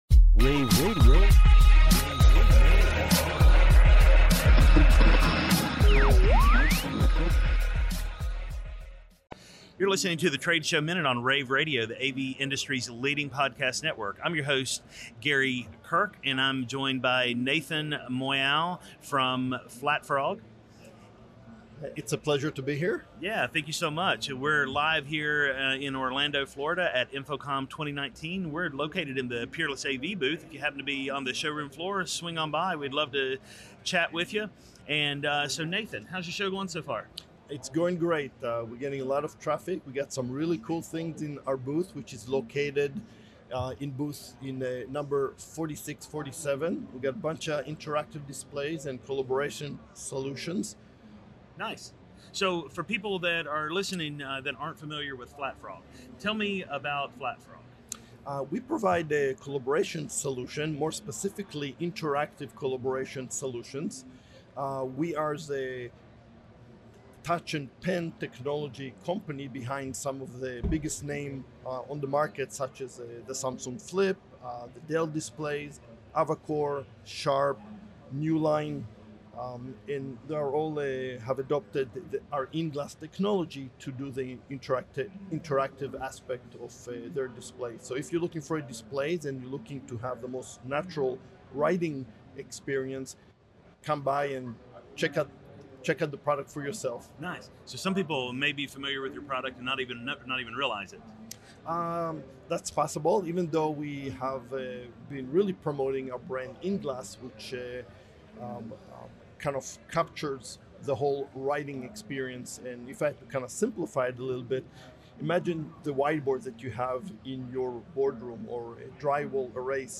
June 14, 2019 - InfoComm, InfoComm Radio, Radio, The Trade Show Minute,